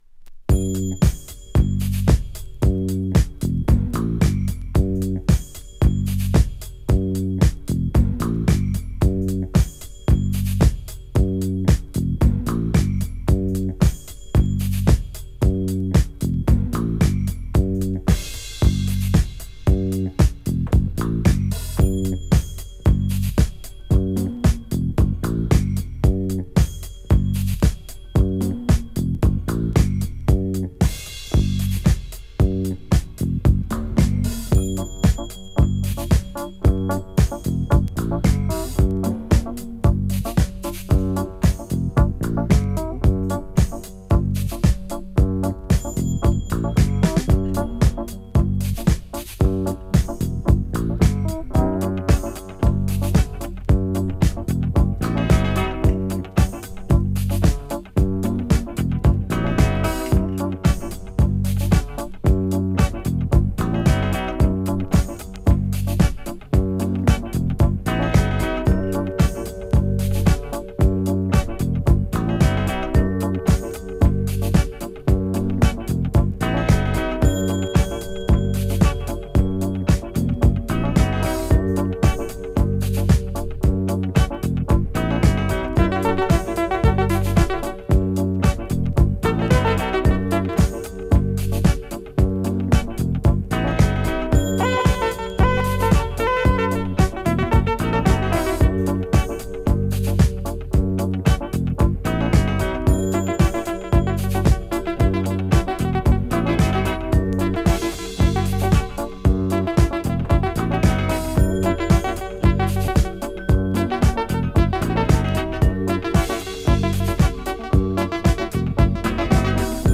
ボーカルをカットし、原曲の一番おいしい部分といえる中盤部分をメインに据えた好インスト・エディットに仕上げています。